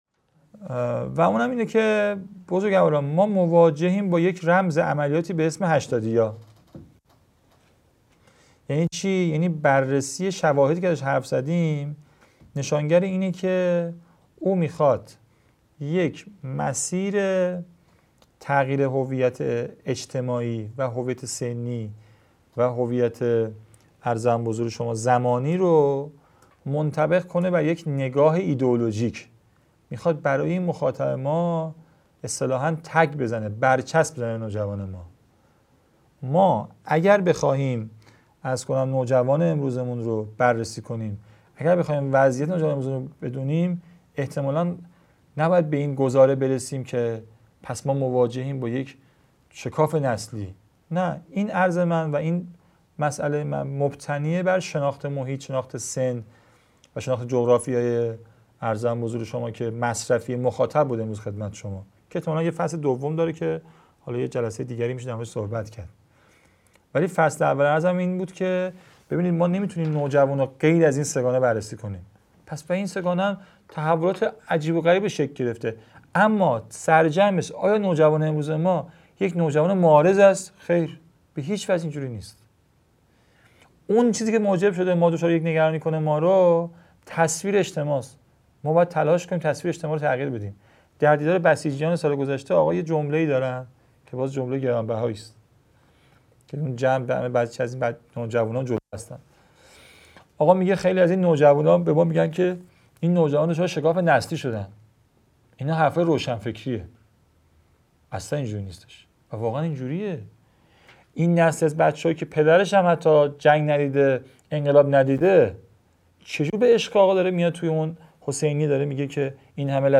مدرسه عالی هیأت | گزیده سیزدهم از دومین سلسله نشست‌ های هیأت و نوجوانان